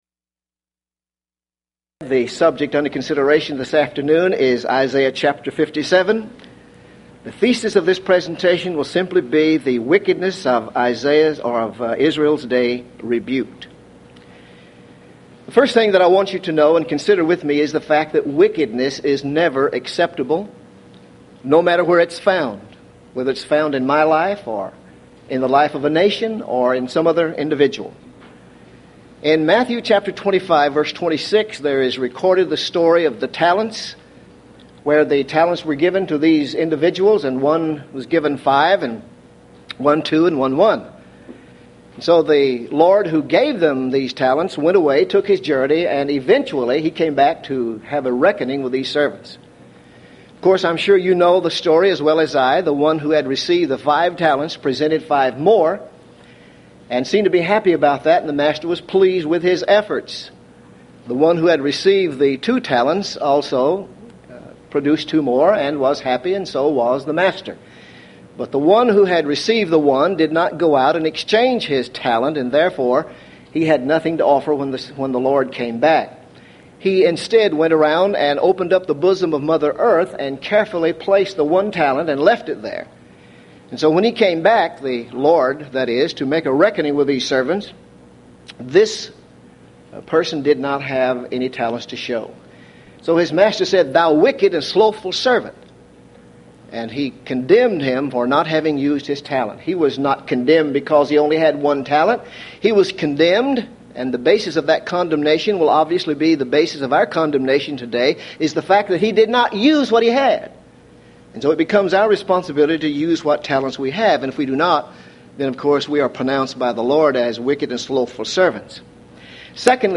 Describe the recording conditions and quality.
Event: 1996 HCB Lectures